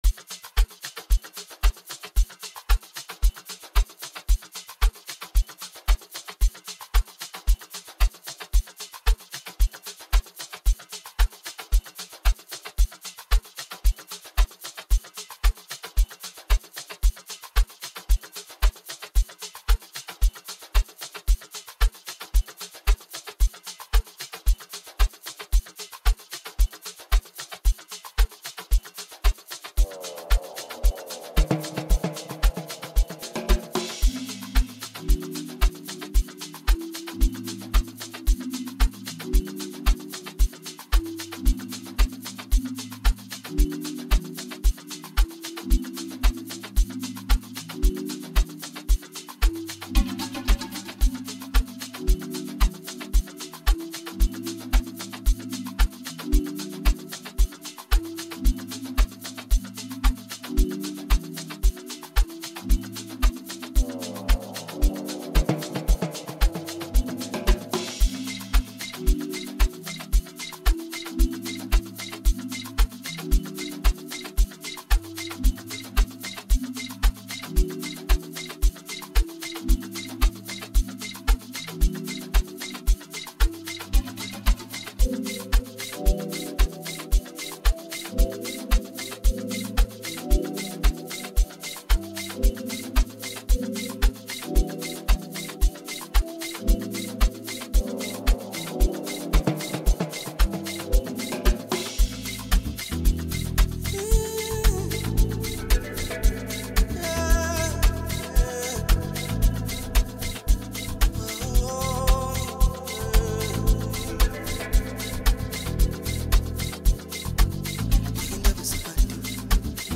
captivating melodies and heartfelt lyrics
catchy rhythm